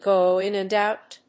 gò ín and óut